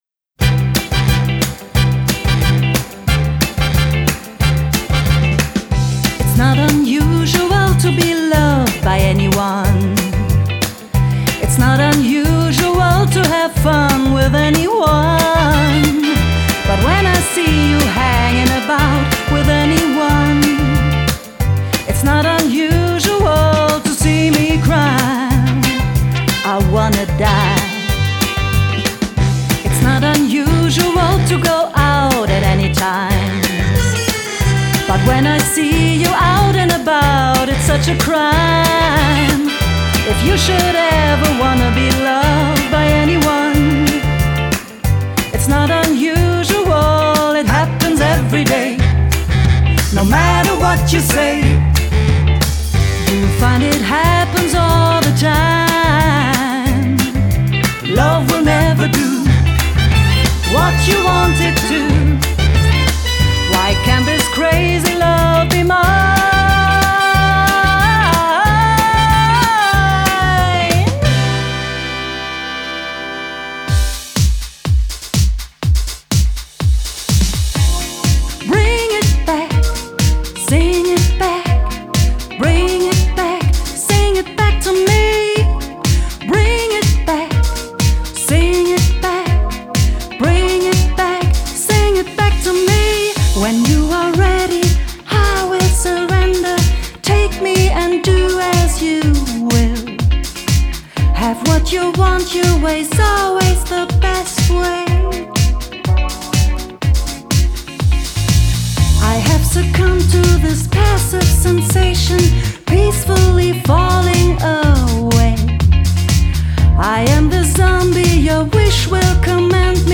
Die glamouröse Soul-Show
Retro-Soul, Pop, Latin, Swing